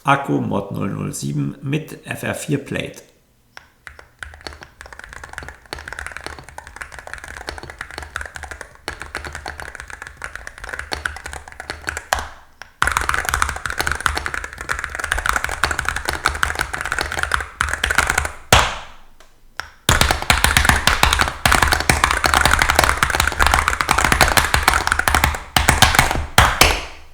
Die alternative Switch Plate nimmt den Anschläge ihre Spitze, sie werden eine Spur dumpfer und tiefer. An der grundlegenden Klangcharakteristik und Präsenz der Tastatur ändert sich nichts.
Die FR4-Switch-Plate lässt die Tastatur etwas dumpfer werden